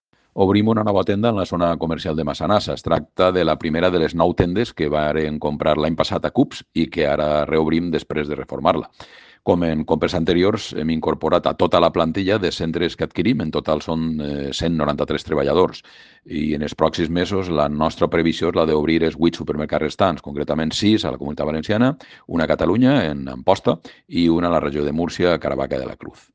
Corte de Voz